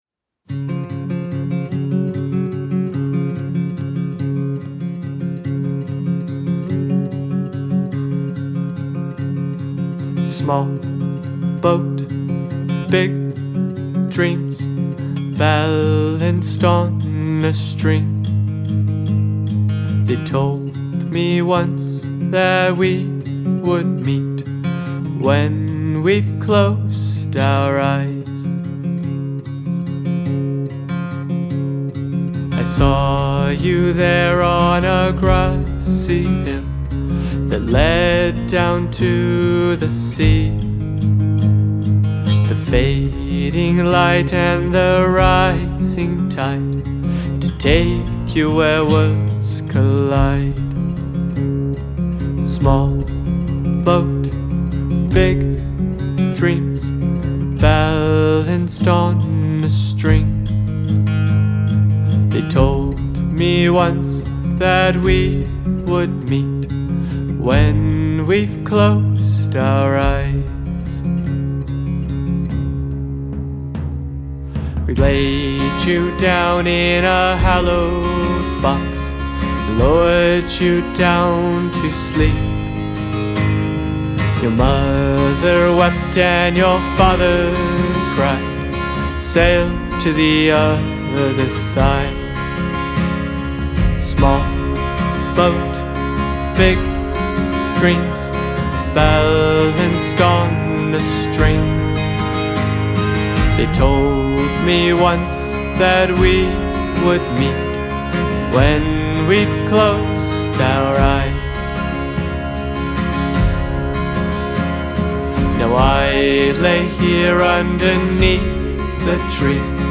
heartfelt acoustic folk track